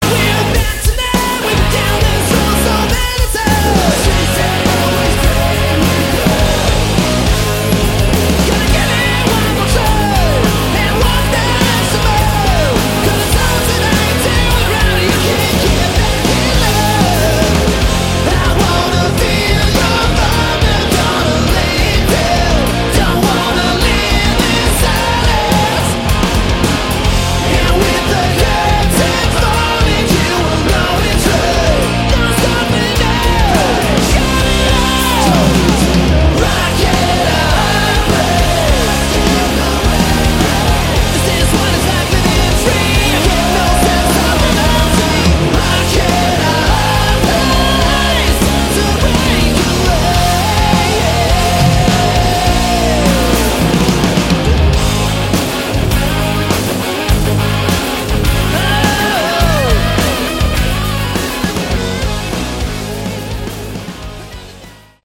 Category: Sleaze Glam
vocals
guitar
drums
bass